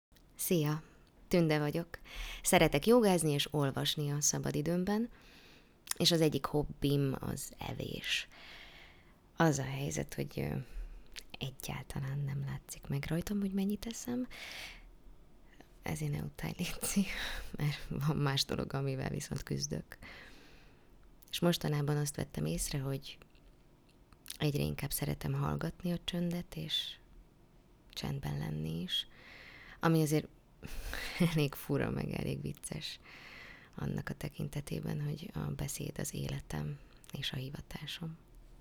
Egyedi kérések alapján készítjük el számodra a hanganyagot, RØDE stúdió mikrofonnal.
színész, logopédus